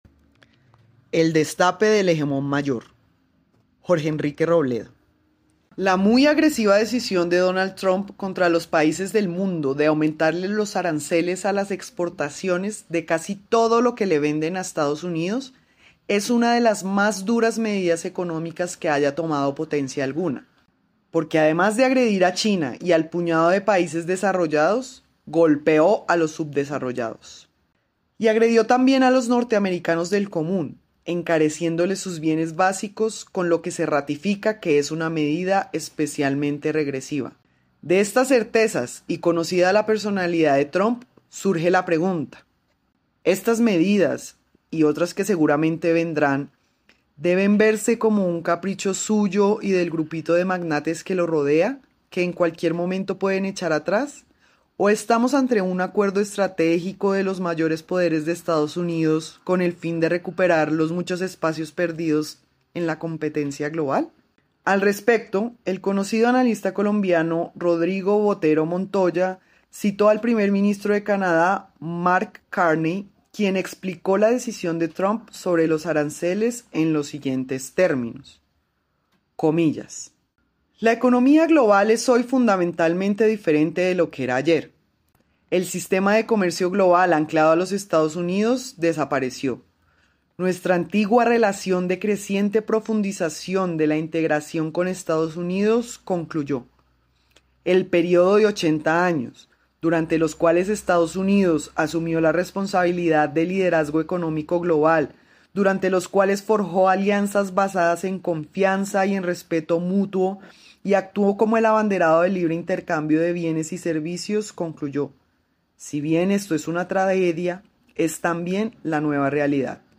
Lectura: